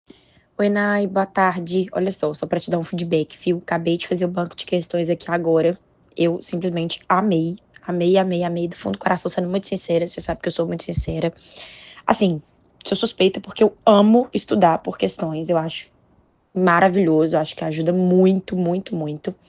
Alguns Áudios dos Alunos
AUDIO-DEPOIMENTO-1.m4a